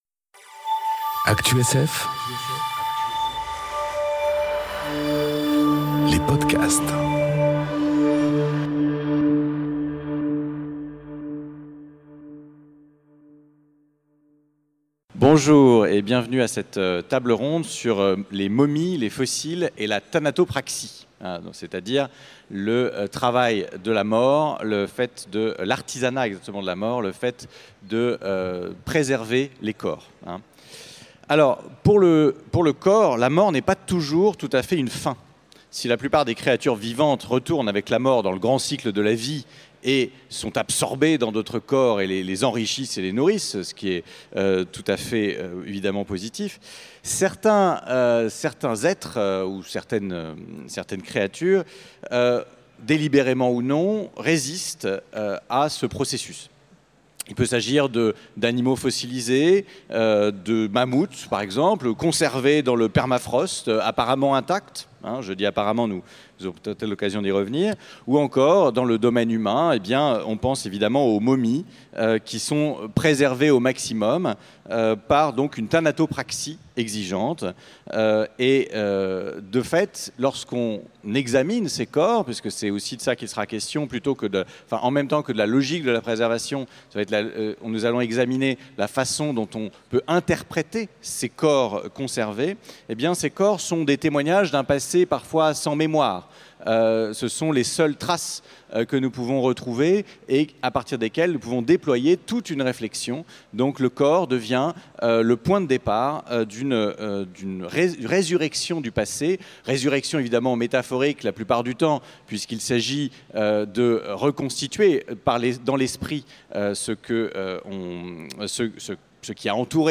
Conférence Momies, fossiles et thanatopraxie enregistrée aux Utopiales 2018